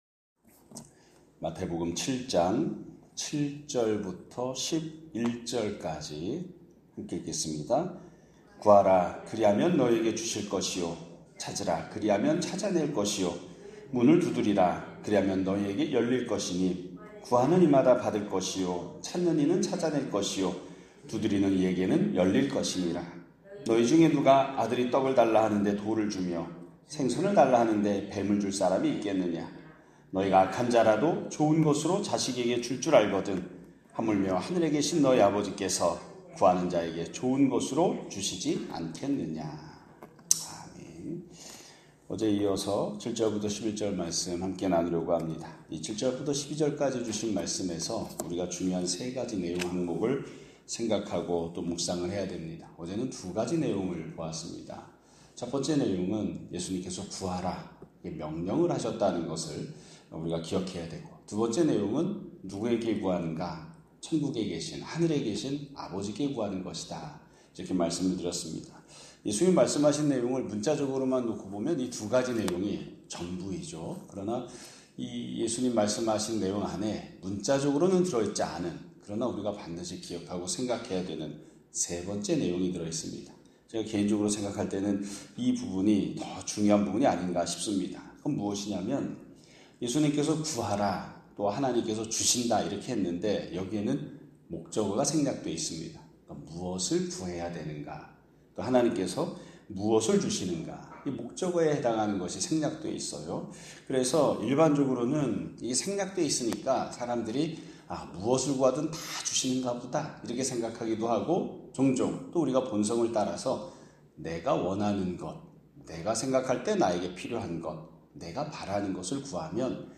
2025년 6월 26일(목요일) <아침예배> 설교입니다.